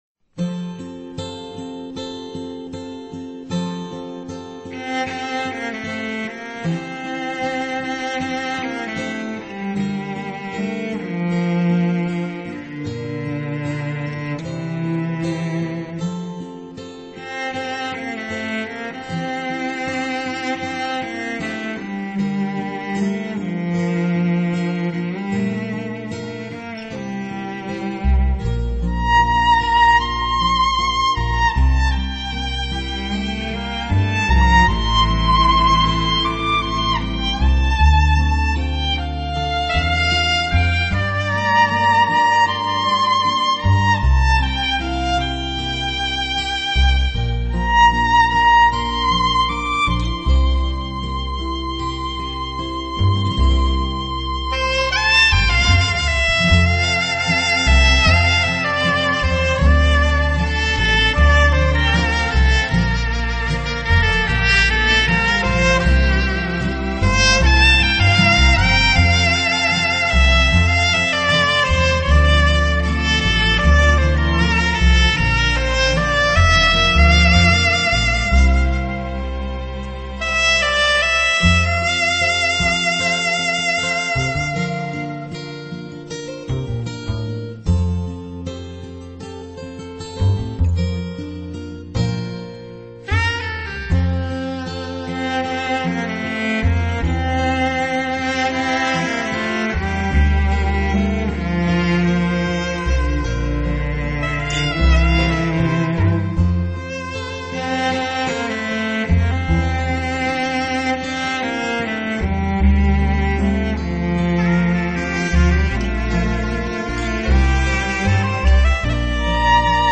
小提琴、大提琴、钢琴、长笛、二胡等中西乐器演绎经典，DSD直接刻录，高度传真，完美尽现！
弦乐四重奏
中提琴
二    胡
吉    他